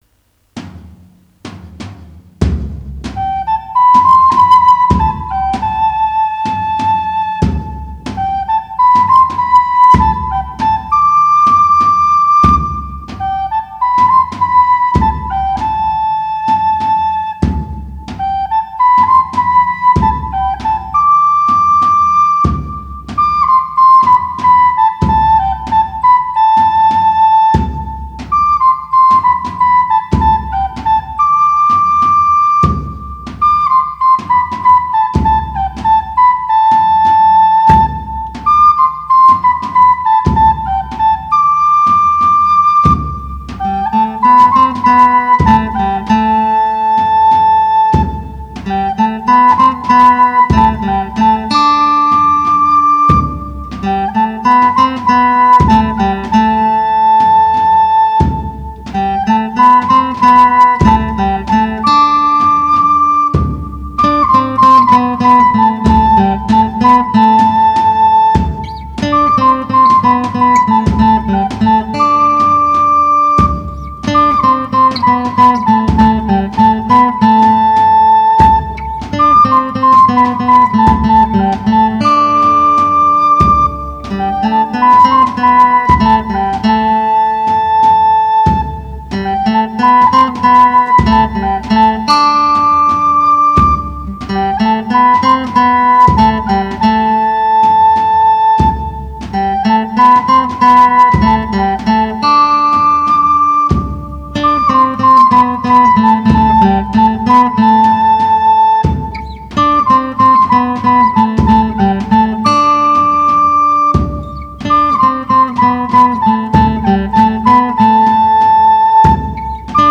Genre: Medieval Folk / Ambient